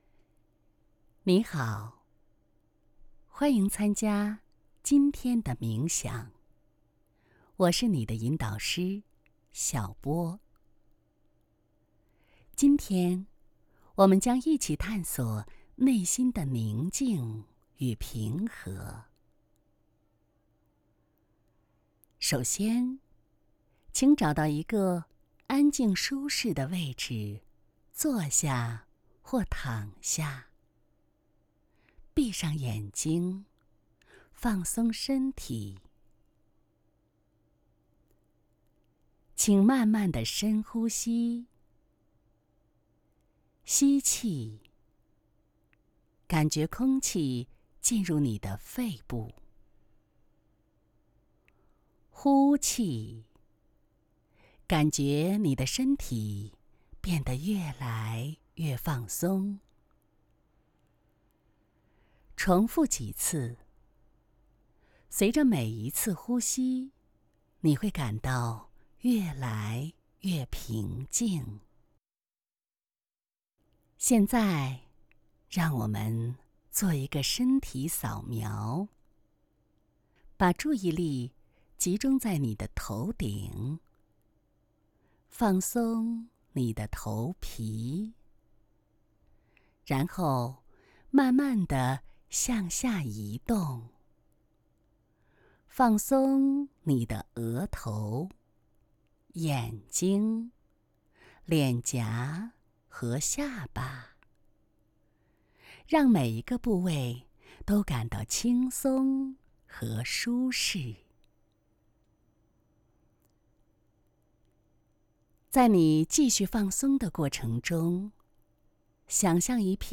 Chinese_Female_001VoiceArtist_40Hours_High_Quality_Voice_Dataset
Calm Style Sample.wav